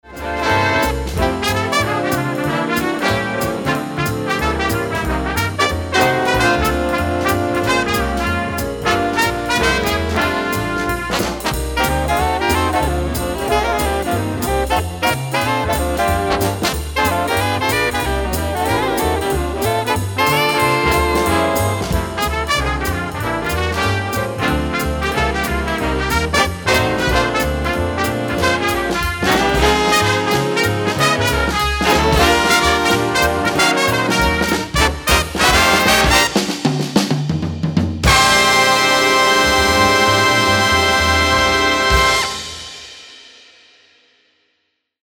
Tenor sax I has jazz solo.
A straight ahead single tempo swing tune (qtr = 185).